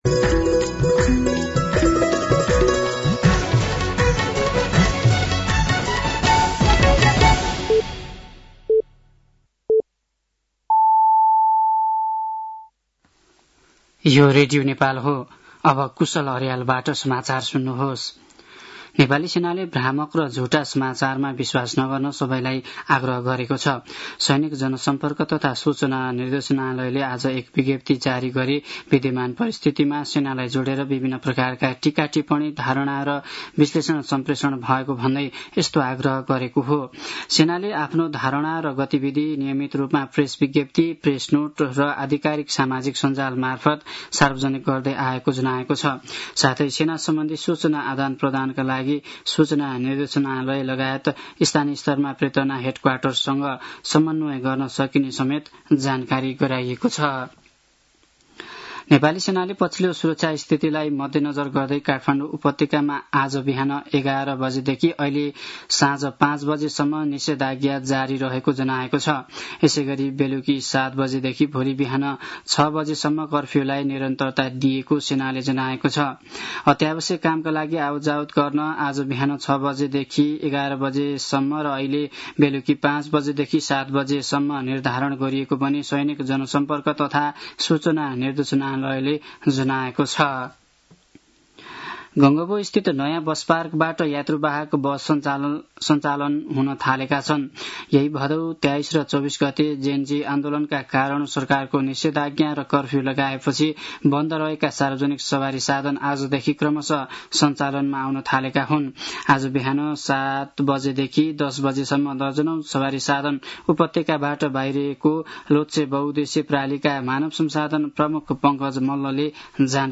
साँझ ५ बजेको नेपाली समाचार : २७ भदौ , २०८२